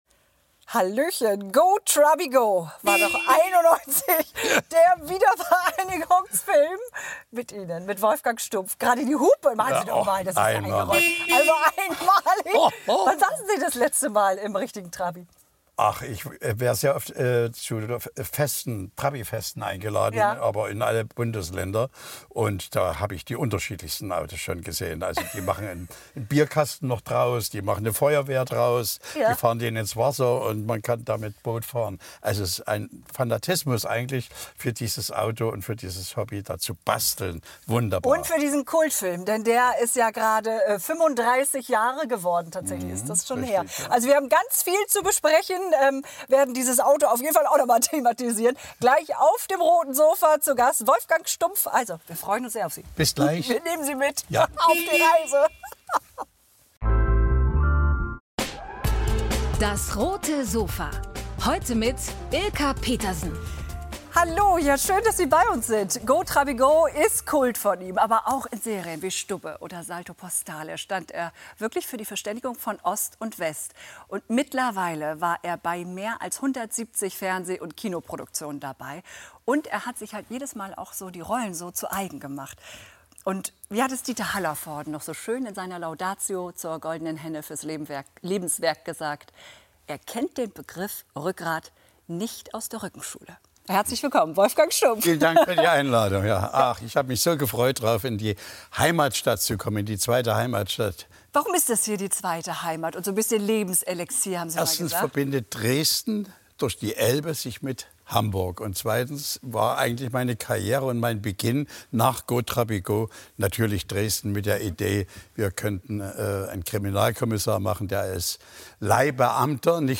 Schauspieler Wolfgang Stumph im Talk